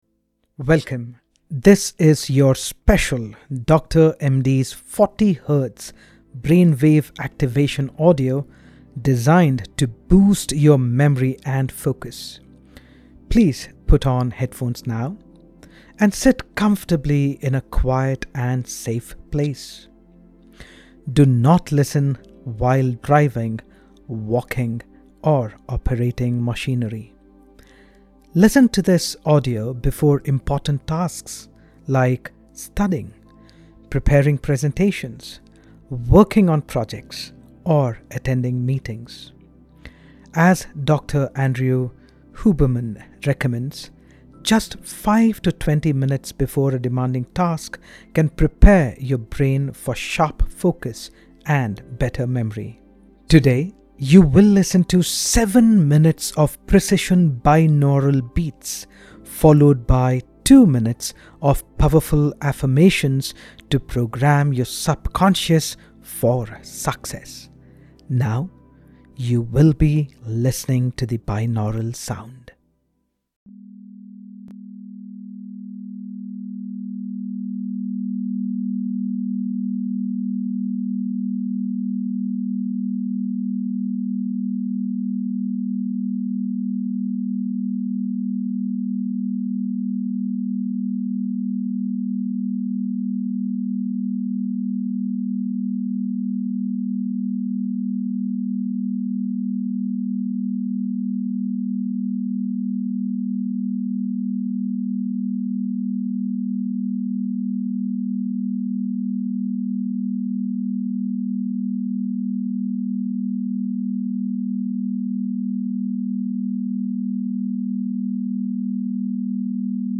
Use stereo headphones (not speakers).